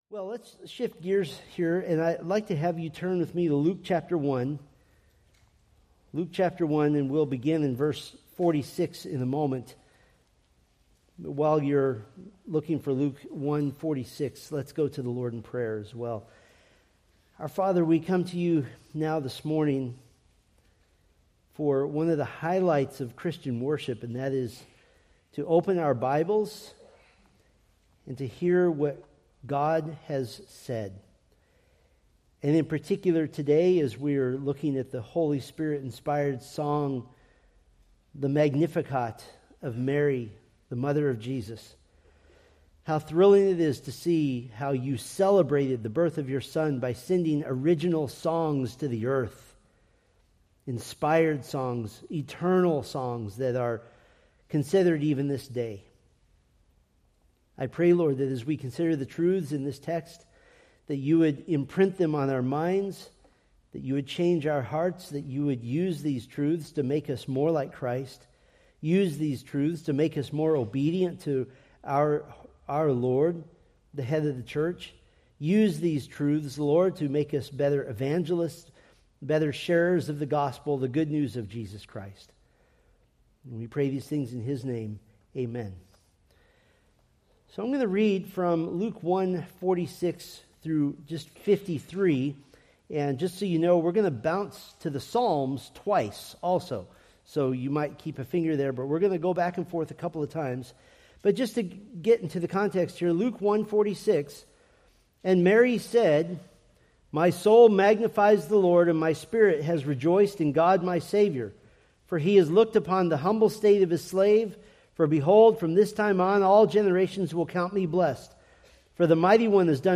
Preached December 14, 2025 from Luke 1:50-53